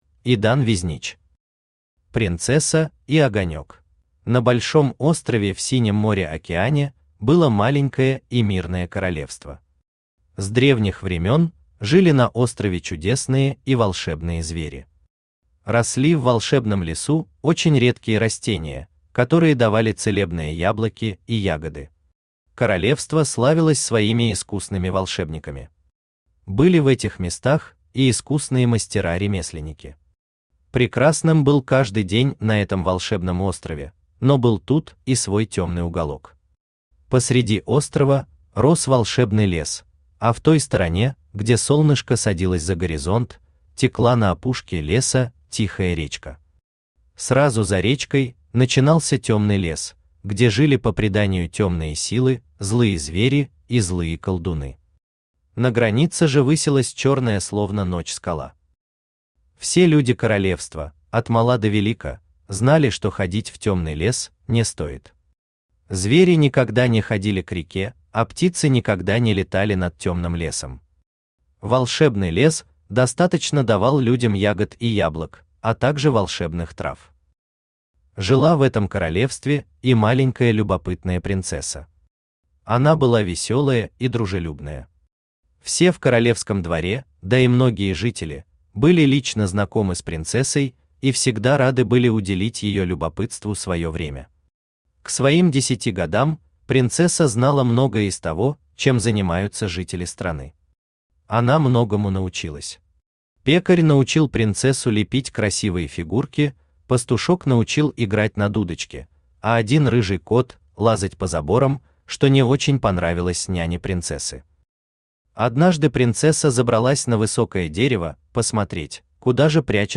Аудиокнига Принцесса и огонёк | Библиотека аудиокниг
Aудиокнига Принцесса и огонёк Автор Идан Везнич Читает аудиокнигу Авточтец ЛитРес.